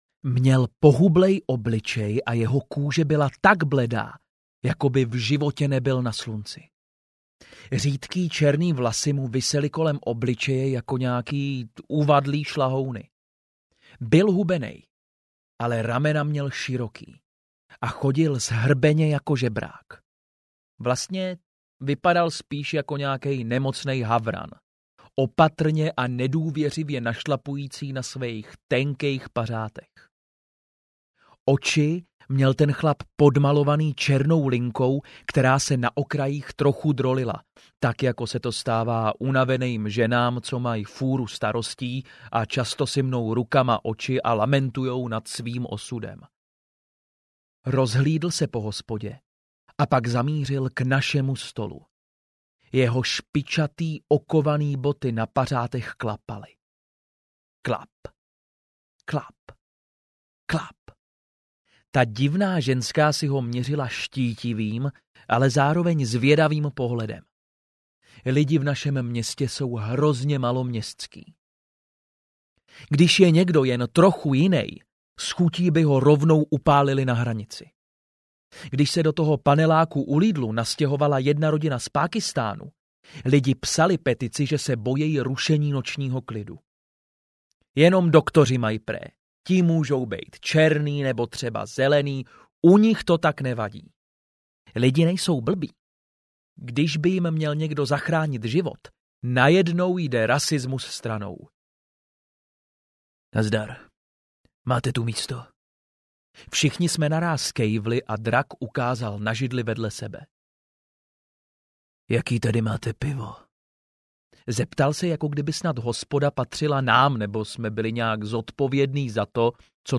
Démon ze sídliště audiokniha
Ukázka z knihy